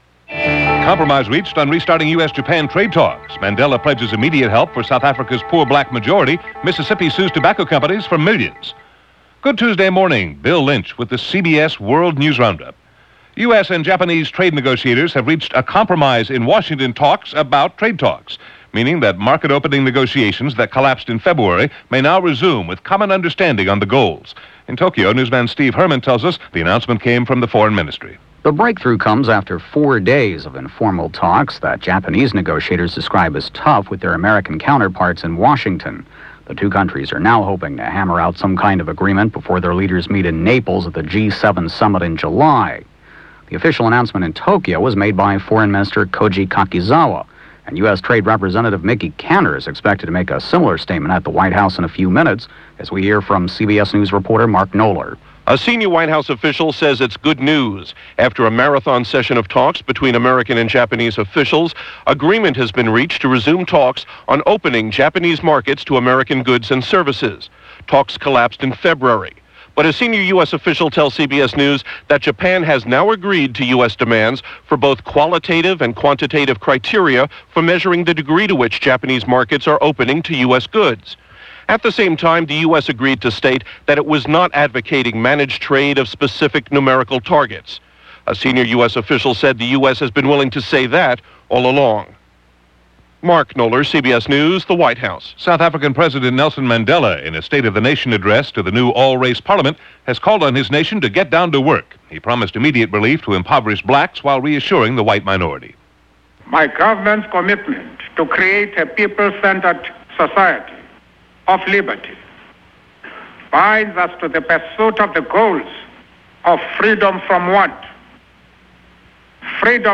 Meanwhile, in South Africa; President Nelson Mandela gave his State Of The Nation address to an all-race Parliament, urging his country to get to work, promising immediate relief to impoverished Blacks while reassuring the White minority: